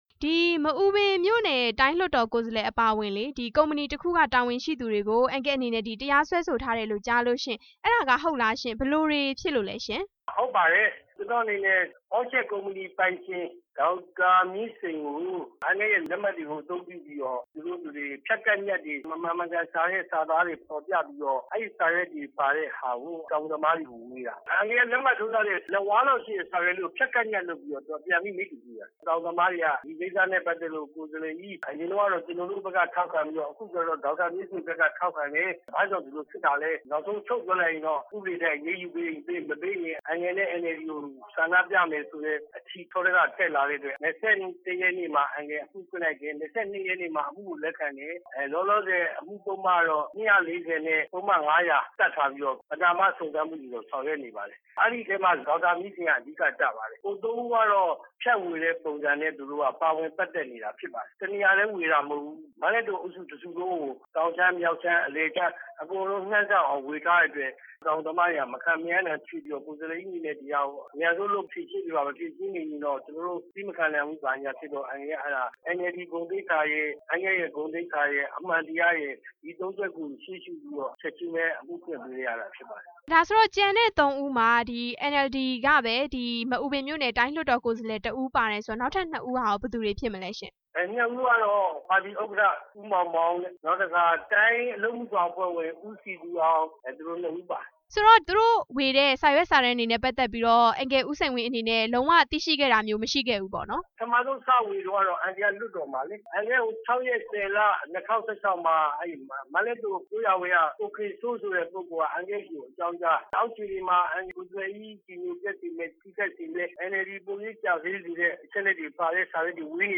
တိုင်းလွှတ်တော်ကိုယ်စားလှယ် ၄ ဦးကို အမှုဖွင့်တဲ့ အကြောင်း မေးမြန်းချက်